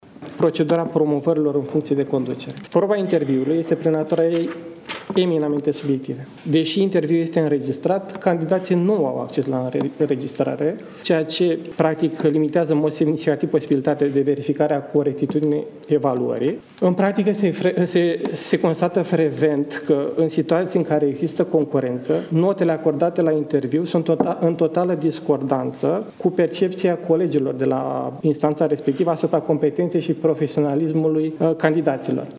Întâlnirea de la Palatul Cotroceni cu magistrați și alți actori din sistemul judiciar